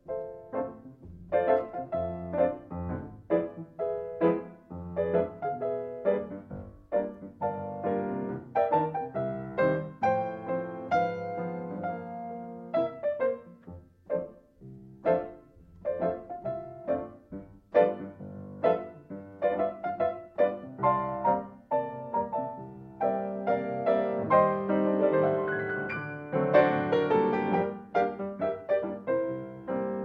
5 -- Valse improvisée dans le style fox-trott